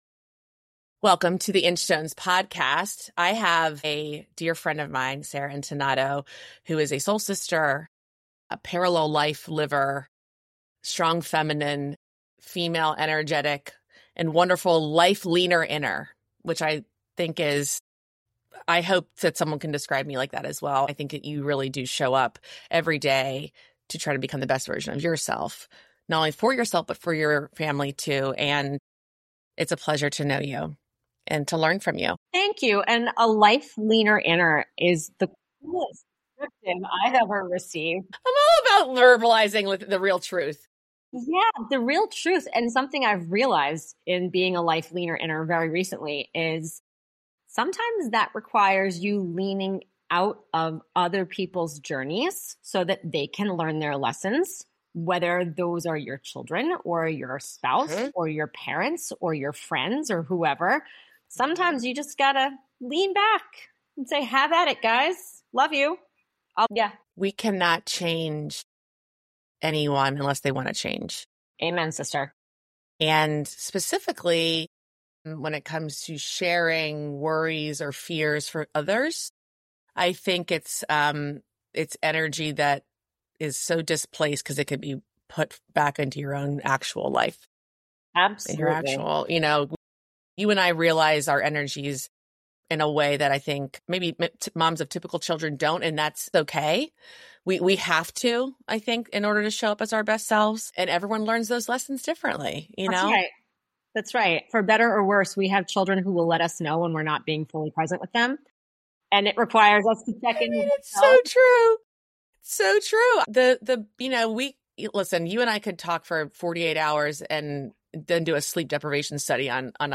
This conversation is a must-listen for any parent navigating autism, personal growth, or the spiritual challenges of caregiving.